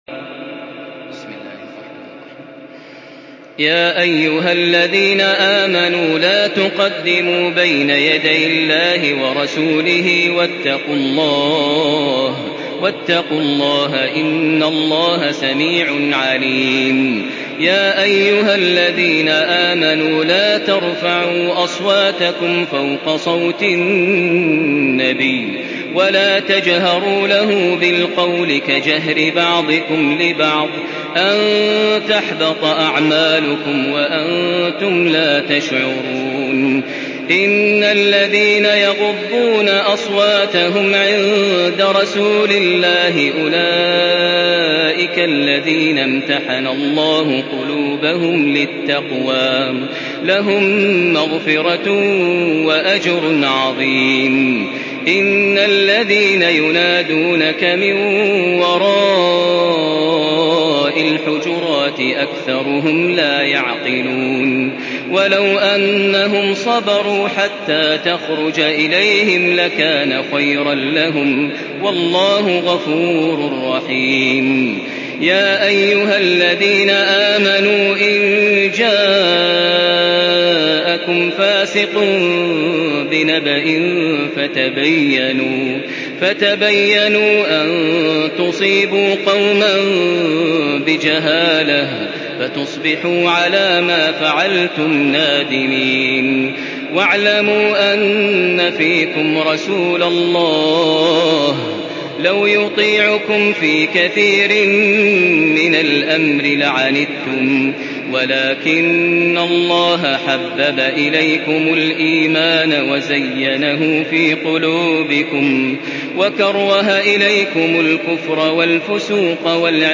سورة الحجرات MP3 بصوت تراويح الحرم المكي 1435 برواية حفص عن عاصم، استمع وحمّل التلاوة كاملة بصيغة MP3 عبر روابط مباشرة وسريعة على الجوال، مع إمكانية التحميل بجودات متعددة.
تحميل سورة الحجرات بصوت تراويح الحرم المكي 1435